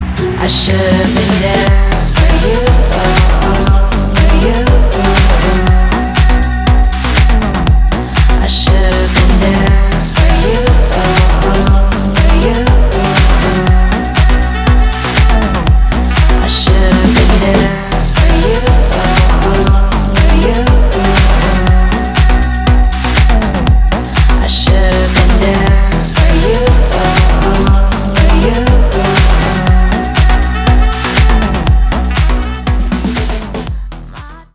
громкие
Electronic
EDM
красивый женский голос
клубняк
Стиль: house